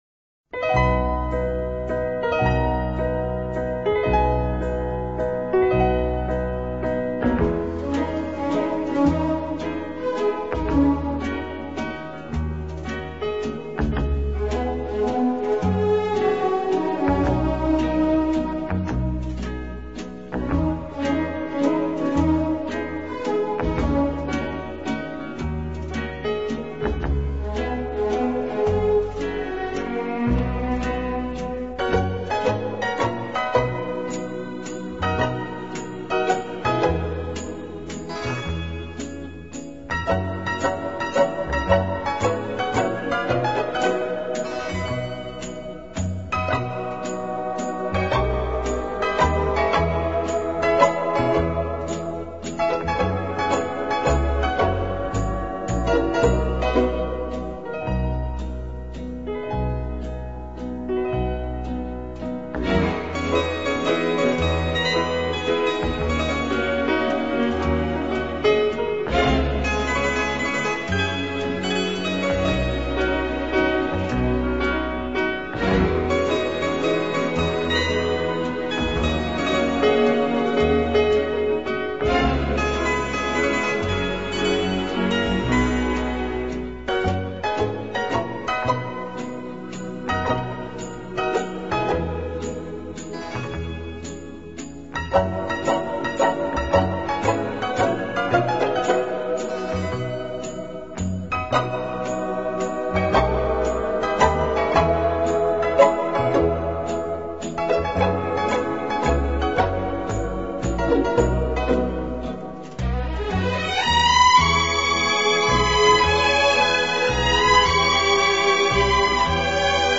音樂類型: 演奏音樂